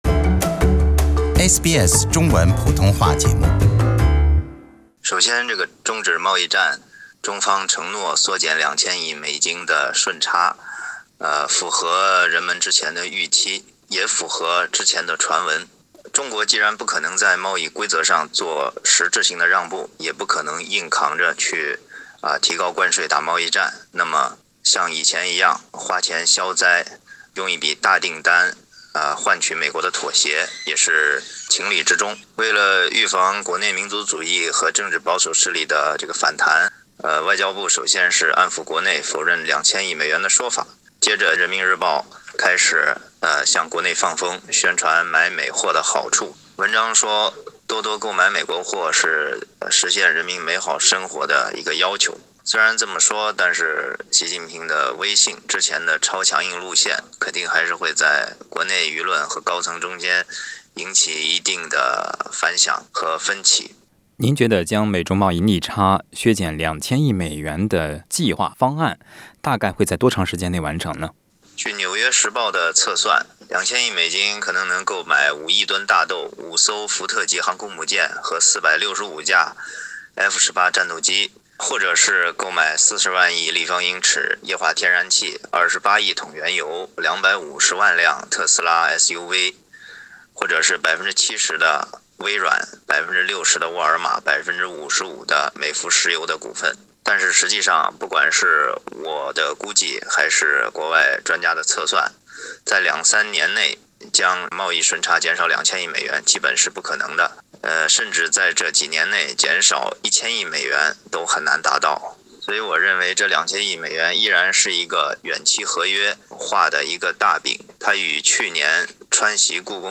更详细的分析点评，请点击收听详细的采访内容。